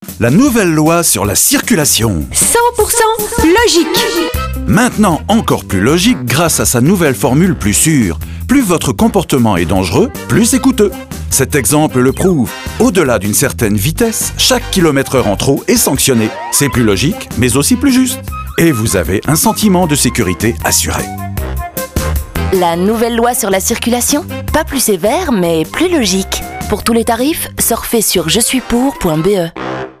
Masda (Bruxelles), pub